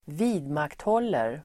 Uttal: [²v'i:dmakthål:er]